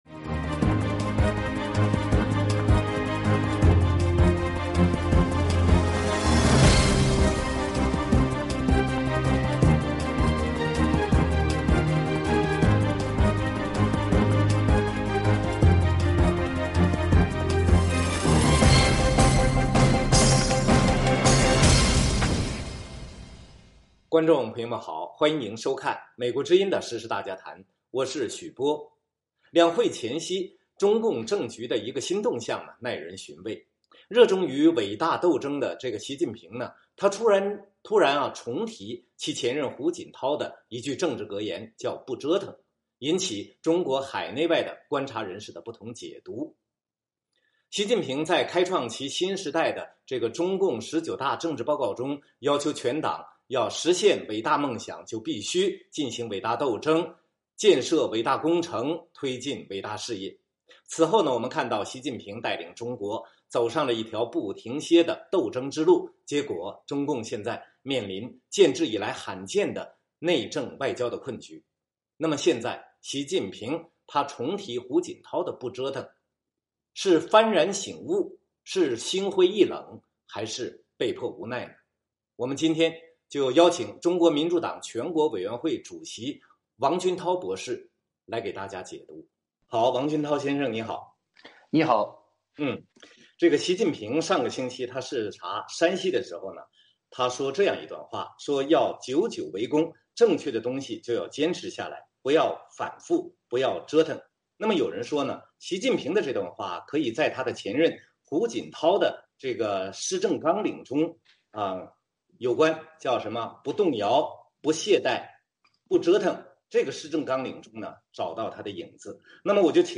我们邀请中国民主党全国委员会主席王军涛博士来给大家解读。